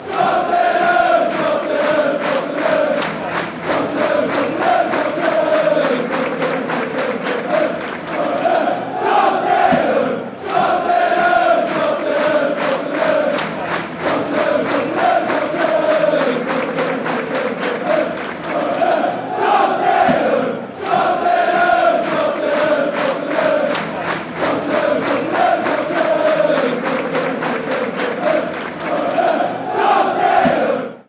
soccer chant